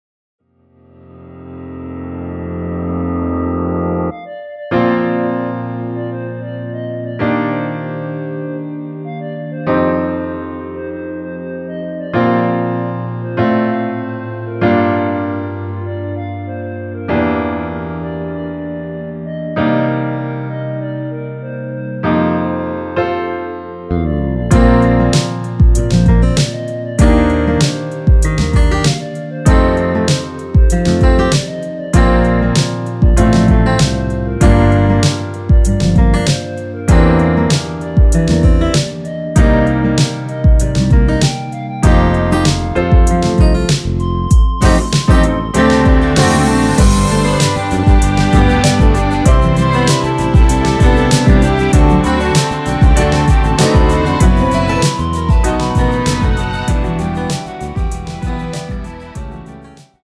원키 멜로디 포함된 MR 입니다.(미리듣기 참조)
Bb
앞부분30초, 뒷부분30초씩 편집해서 올려 드리고 있습니다.
중간에 음이 끈어지고 다시 나오는 이유는